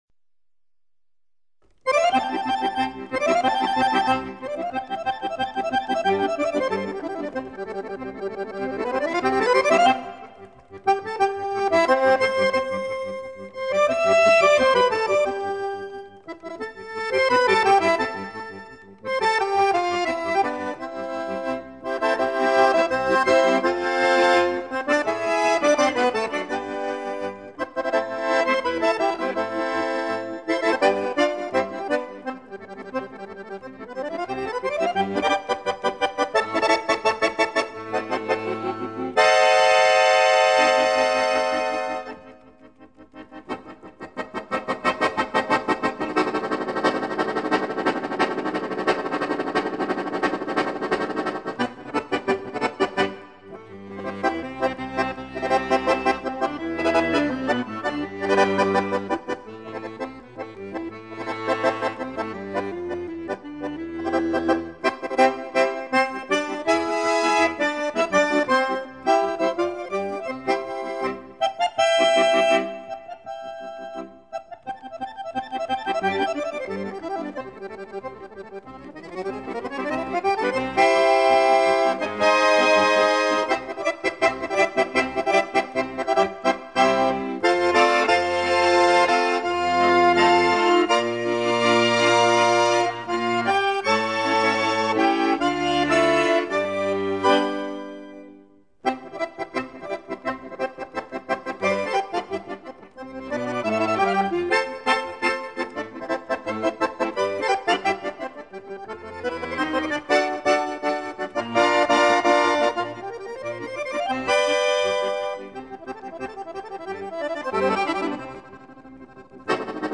本专辑由十余位国际、国内手风琴大赛获奖者演奏。